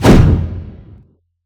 Punch Swing_HL_5.wav